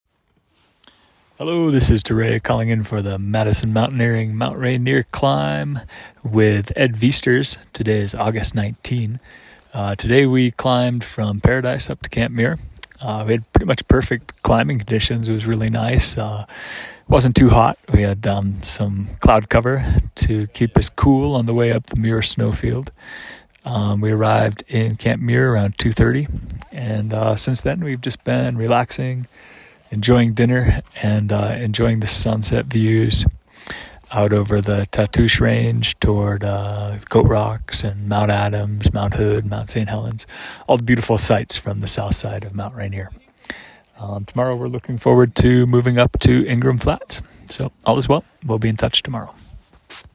checks in with this dispatch from Camp Muir